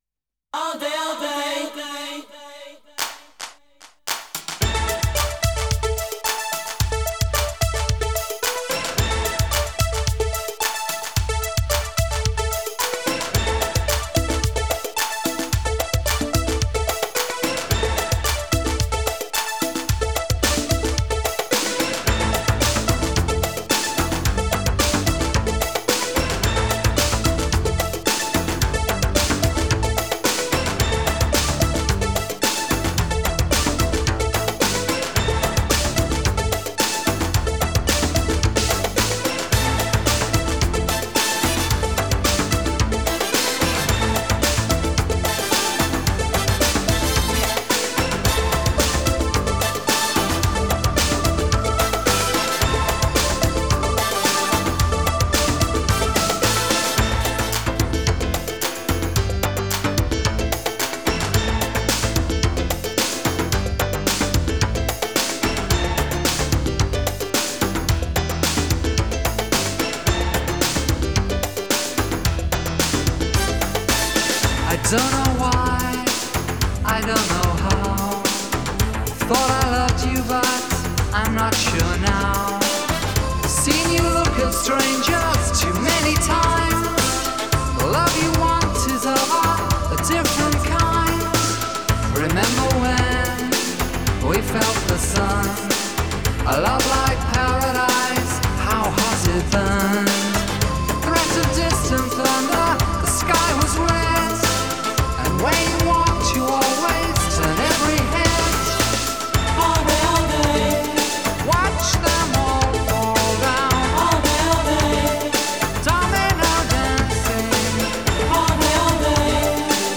Disco Italo Disco
House, Synth Pop, Disco